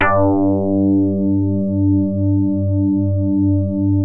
JAZZ HARD G1.wav